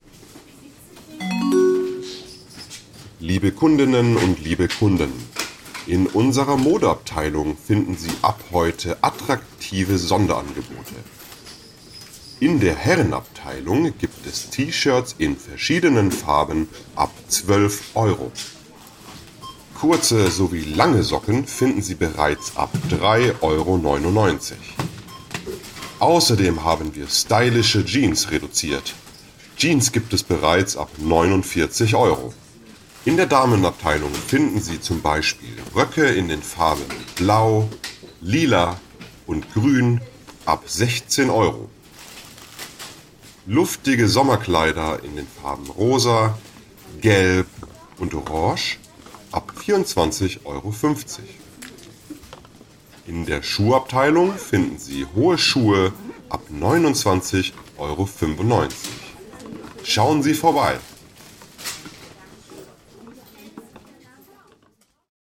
Supermarkt Durchsage
Supermarkt-Durchsage.mp3